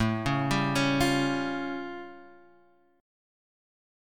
A+7 chord